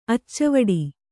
♪ accavaḍi